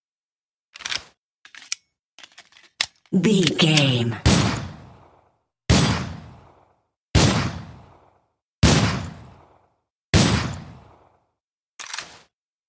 Pistol Equip, Clip eject, Insert, Reload, Firing and Unequip 01 | VGAME
Filled with 10 sounds(44/16 wav.) of Pistol Equip, Shells Insert, Reload, Firing(Five single shots) and Unequip.
Sound Effects
Adobe Audition, Zoom h4
pistol
firing